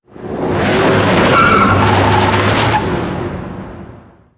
Derrape de un coche
coche
derrape
Sonidos: Transportes